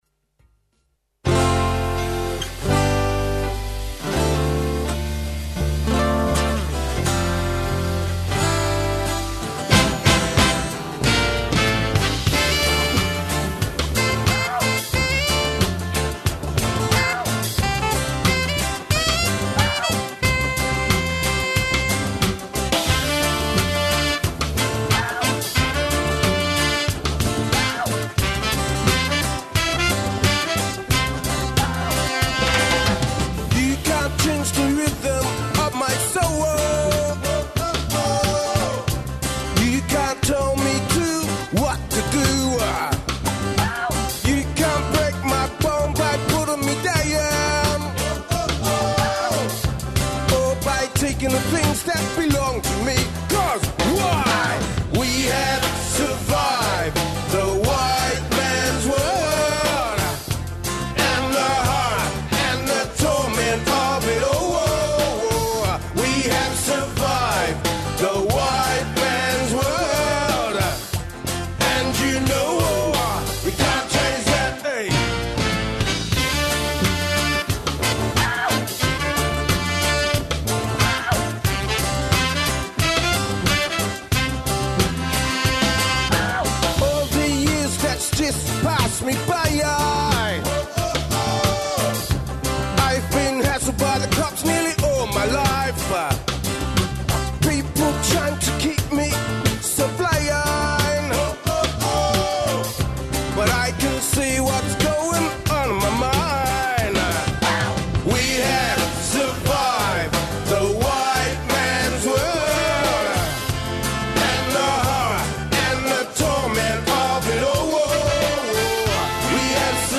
In 2020, 3CR marked Invasion Day with special programming and live coverage of the Melbourne rally. Across seven hours, our programming revealed the real history of Australia, covered local and interstate events and rallies, demanded justice and celebrated the survival and culture of Aboriginal people.
Listen back to some of the audio broadcast on 3CR on Sunday 26 January from 9am - 4pm.